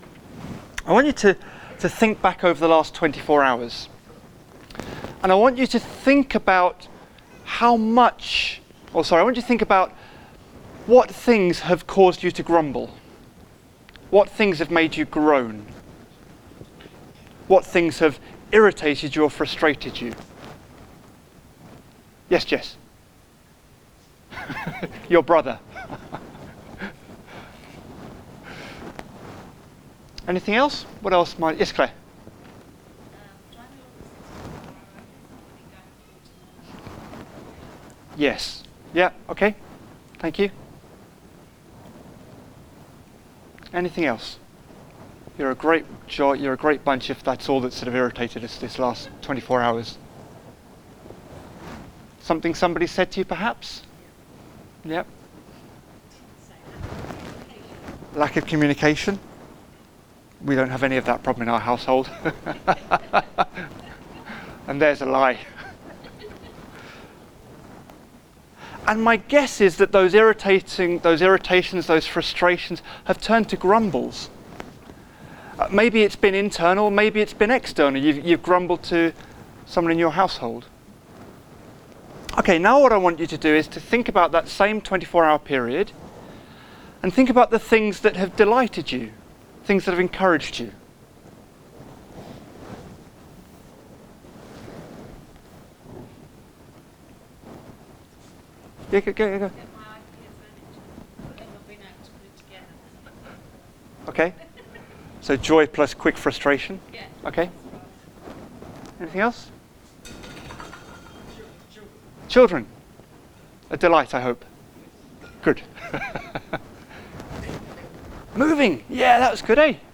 Single Sermon | Hope Church Goldington